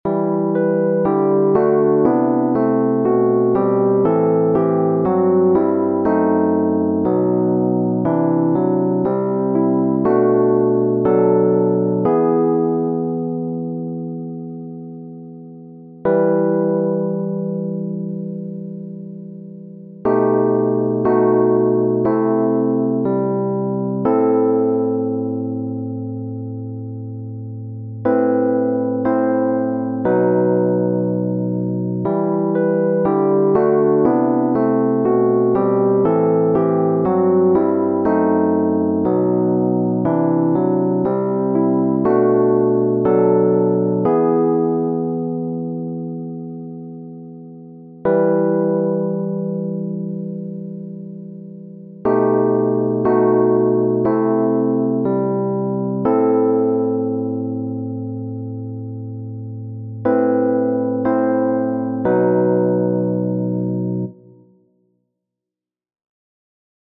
Listen (not a great recording but you get the idea):